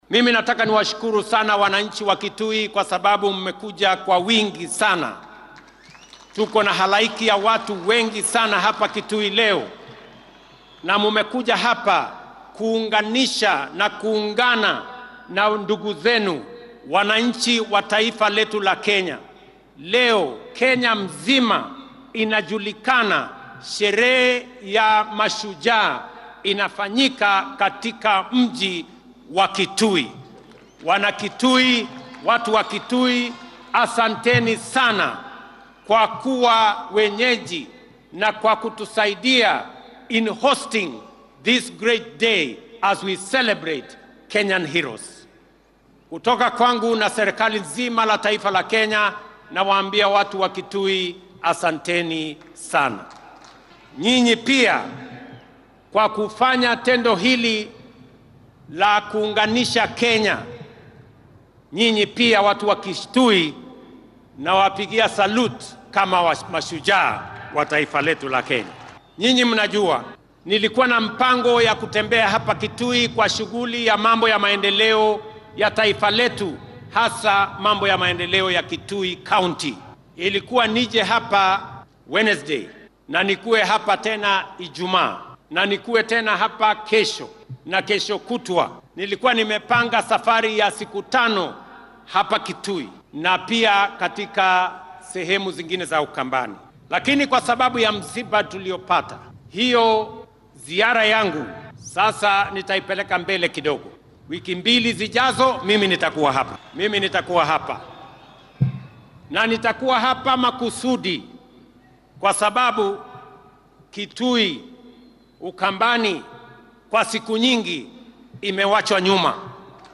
Xuska Mashuja Day ayaa maanta lagu qabtay garoonka Ithookwe ee ismaamulka Kitui.
Dhanka kale madaxweyne Ruto ayaa u mahadceliyay shacabka ismaamulka Kitui isagoona sheegay in usbuuca dambe uu dowlad deegaankaas ku laaban doono si uu uga shaqeeyo hormarka maamulkaas.